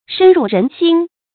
深入人心 shēn rù rén xīn 成语解释 深深地进入人们的心里。
成语简拼 srrx 成语注音 ㄕㄣ ㄖㄨˋ ㄖㄣˊ ㄒㄧㄣ 常用程度 常用成语 感情色彩 中性成语 成语用法 动宾式；作谓语、宾语；含褒义 成语结构 动宾式成语 产生年代 古代成语 成语辨形 心，不能写作“新”。